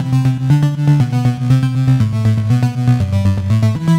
Index of /musicradar/french-house-chillout-samples/120bpm/Instruments
FHC_Arp B_120-C.wav